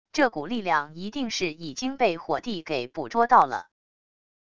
这股力量一定是已经被火帝给捕捉到了wav音频生成系统WAV Audio Player